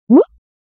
Cartoon-jump-sound-effect.mp3